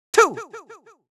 countIn2Farthest.wav